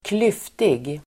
Uttal: [²kl'yf:tig]